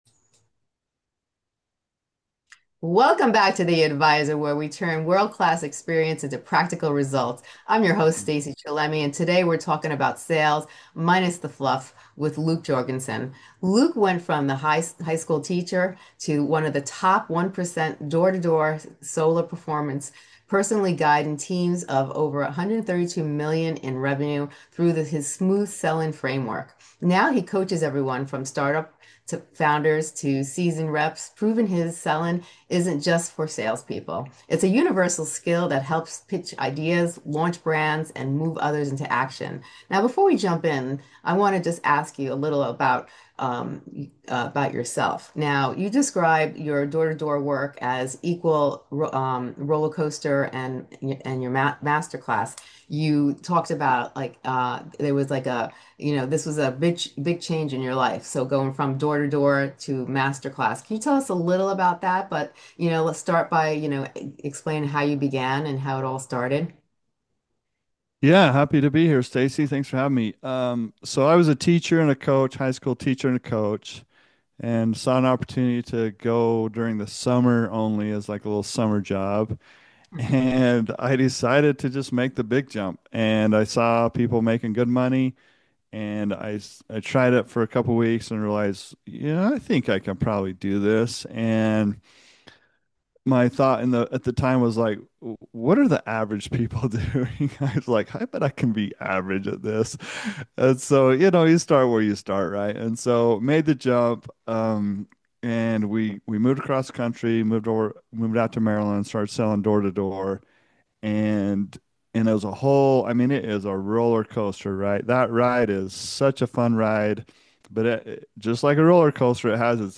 Tune in for candid stories, proven frameworks, and insider tips that will inspire your next breakthrough.